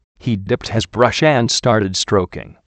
Location: USA
How do you pronounce this word?